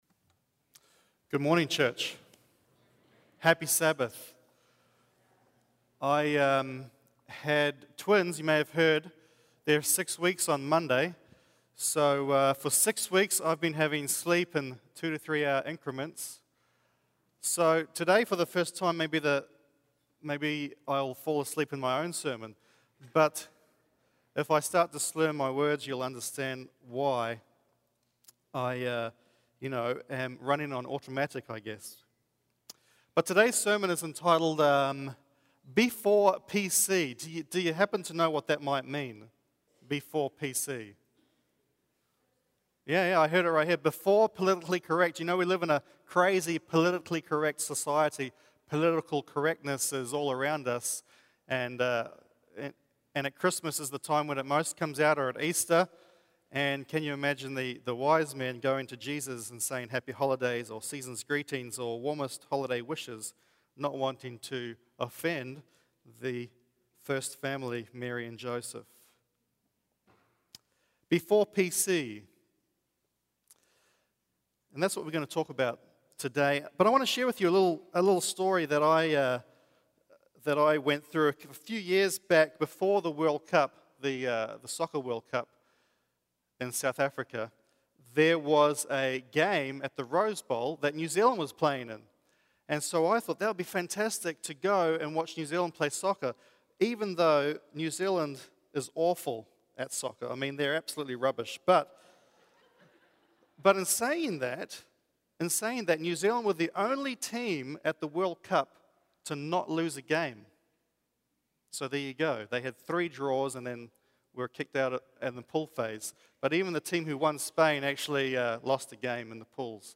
Vallejo Drive Seventh-day Adventist Church - Media Entry